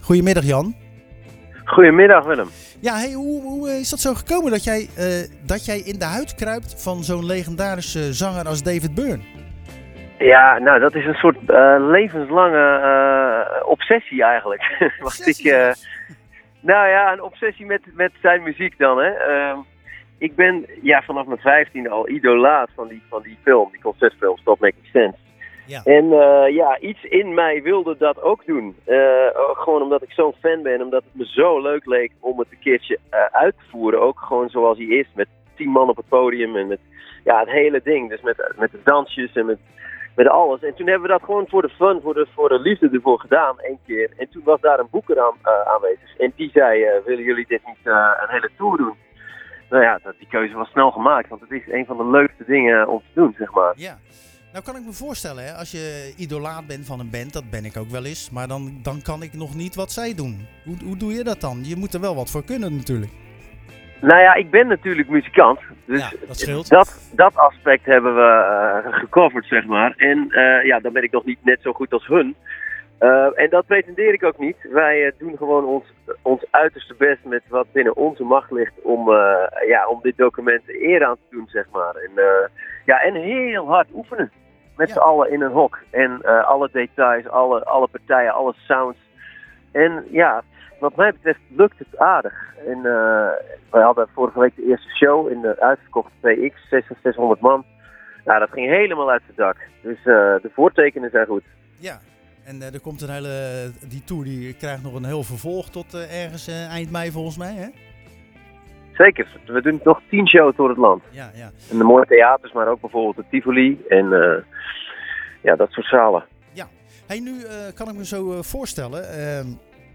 Tijdens de wekelijkse editie van Zwaardvis belde we met zanger